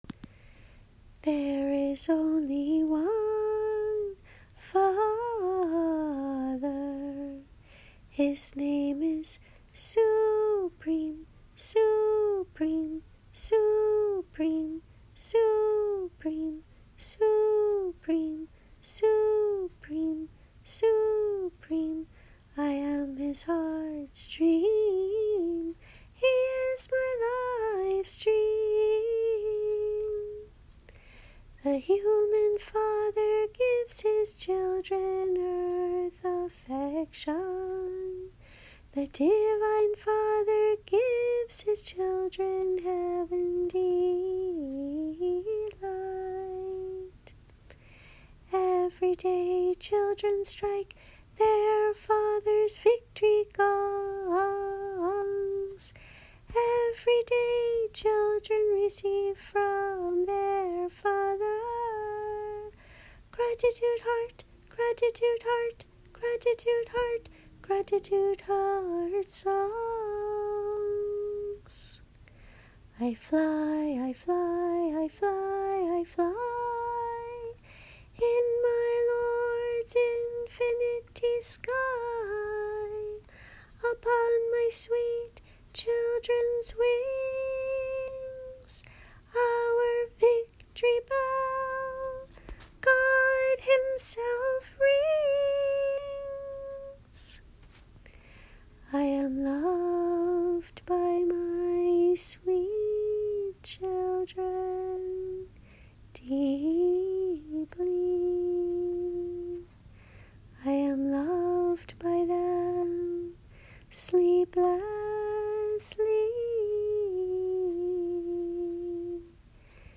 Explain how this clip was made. A recording of the set, sung 1x each.